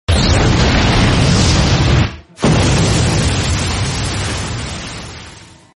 Anime Power Sound Button - Sound Effect Button